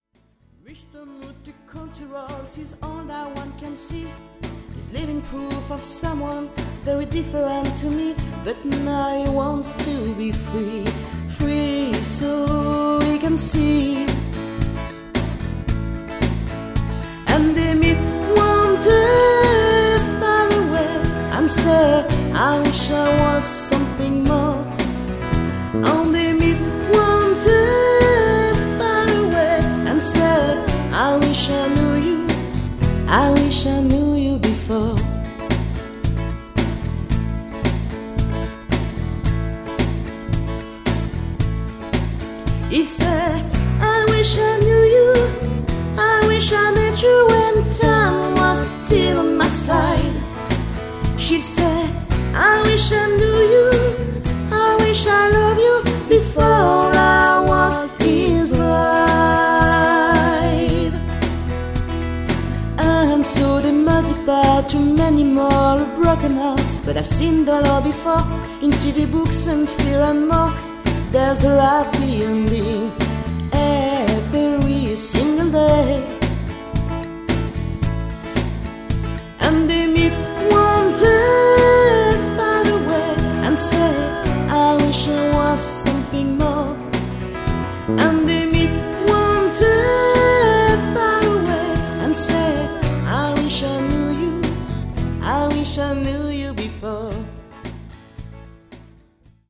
guitare
voix